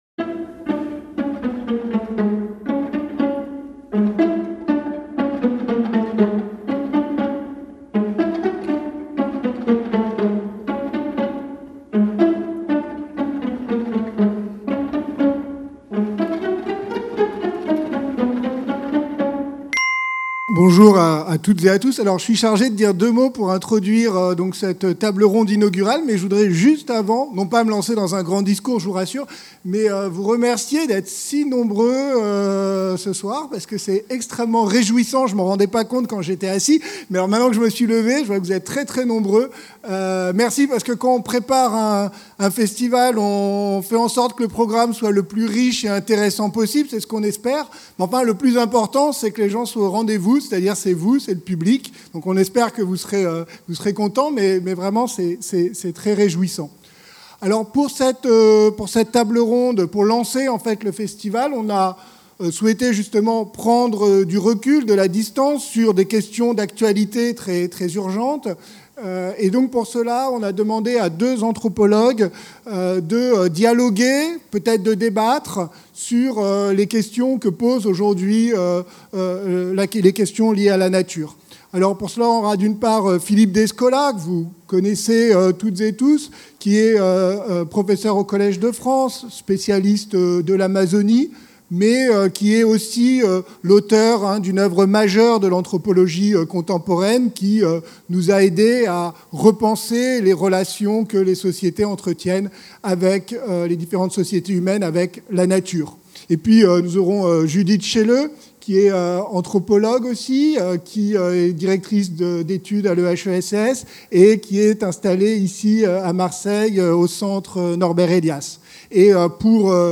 Festival ALLEZ SAVOIR
Cycle de conférences consacré à l’interdisciplinarité...